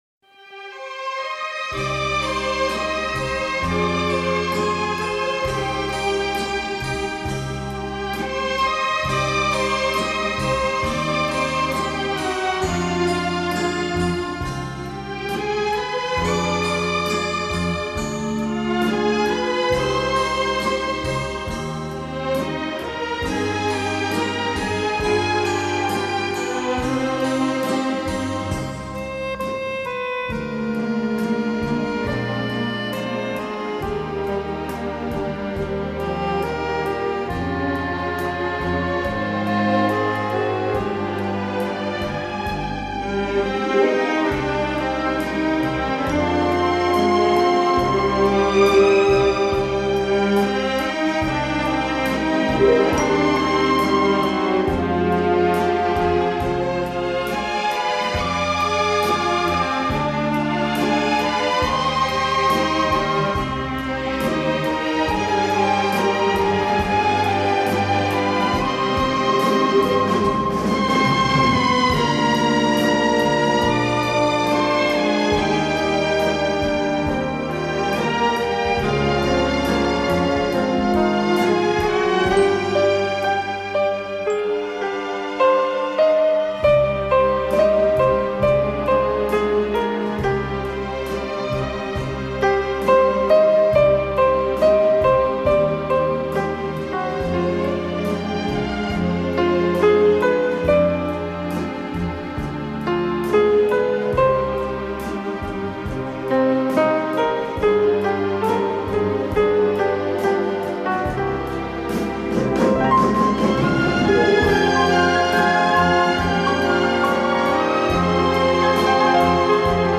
Genres:Pop, Music